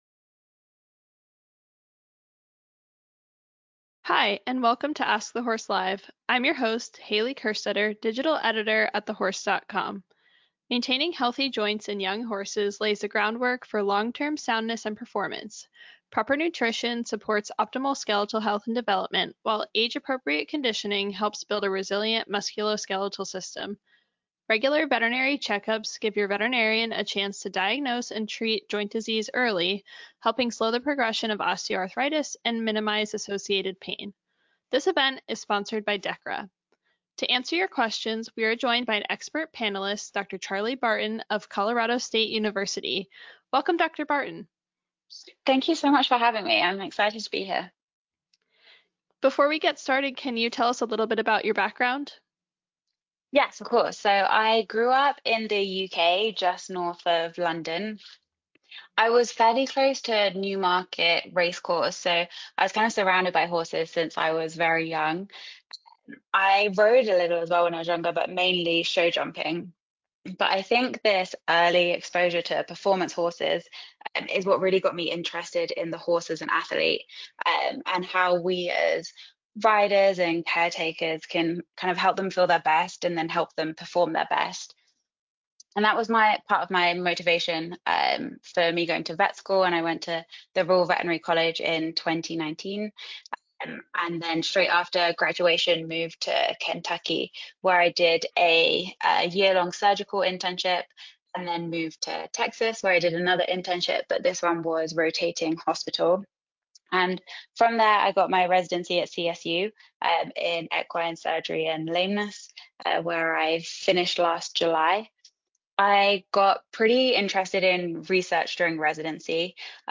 During this Ask TheHorse Live podcast, an equine surgeon answers listener questions about young horse joint care.